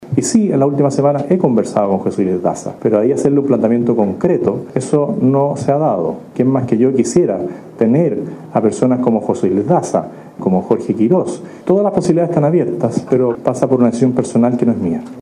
Por ello, es que en un punto de prensa luego del encuentro donde participó la dupla económica de Milei más Jorge Quiroz, Antonio Barchiesi y otros asesores, se le consultó a Kast sobre la posibilidad de nombrar a José Luis Daza como ministro de alguna cartera.